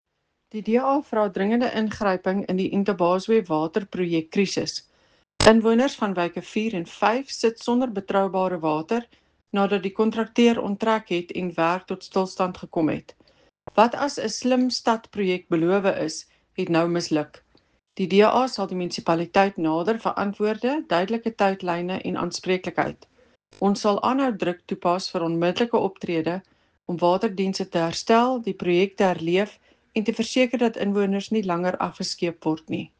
Afrikaans soundbite by Cllr Eleanor Quinta and